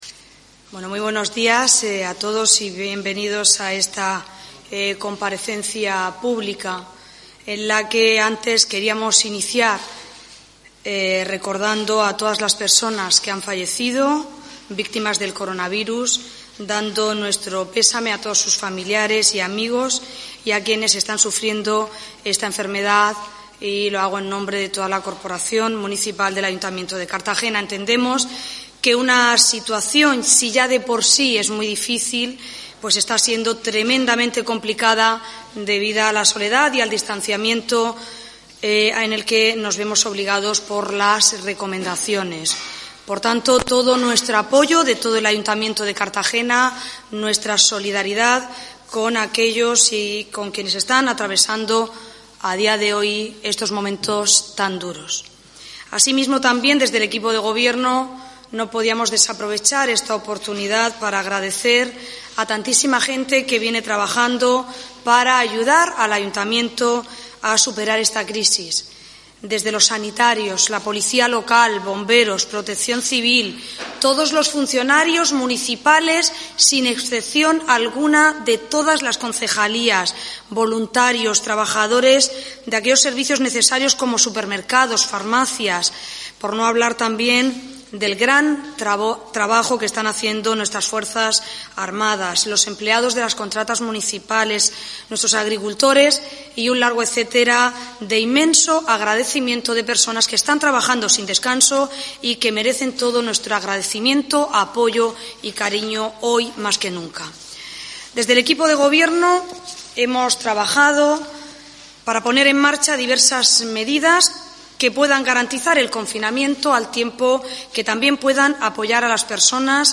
Audio: Declaraciones de la alcaldesa, Ana Bel�n Castej�n,y la vicealcaldesa, Noelia Arroyo, sobre nuevas medidas para afrontar la crisis del coronavirus (MP3 - 14,19 MB)